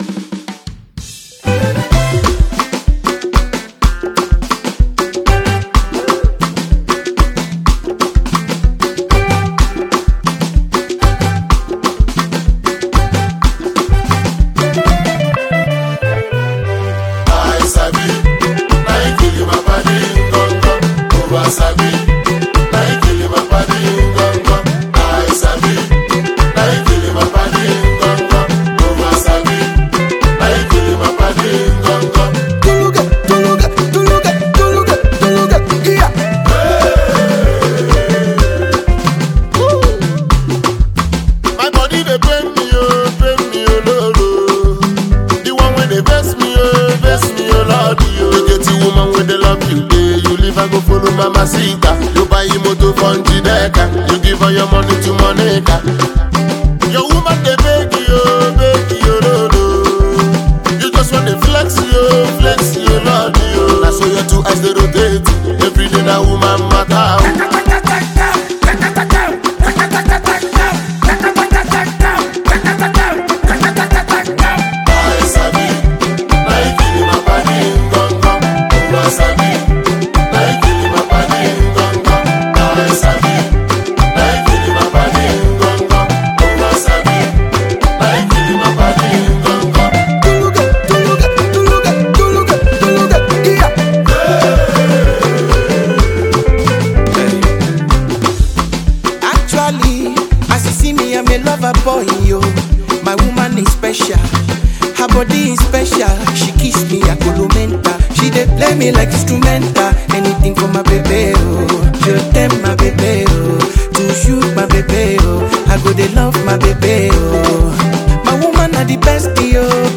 The catchy melody of this song will stay with you forever.